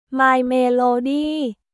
มายเมโลดี้　マイ・メー・ロー・ディー